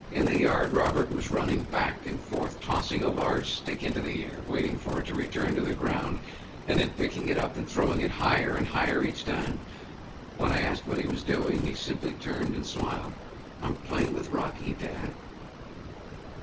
Table 2: Several examples of speech projected onto subsets of cepstral coefficients, with varying levels of noise added in the orthogonal dimensions.